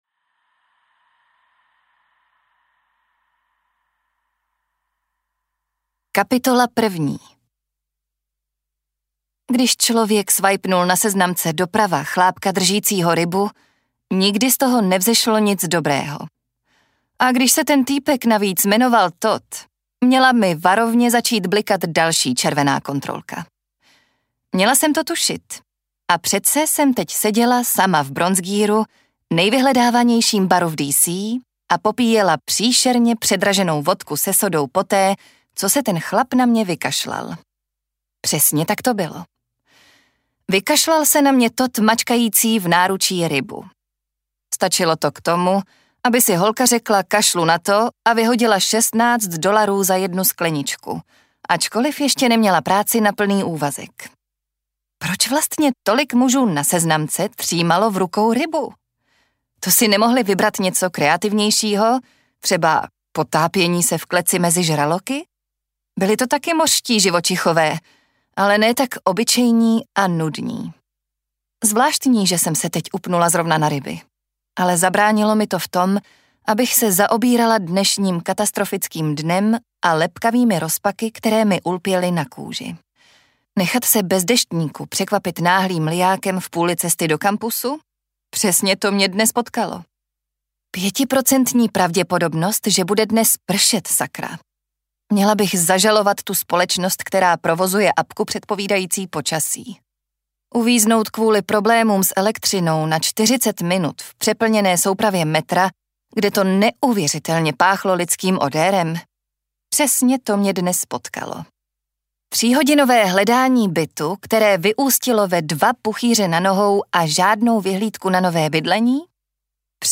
Twisted Hate: Nenávist na ostří nože audiokniha
Ukázka z knihy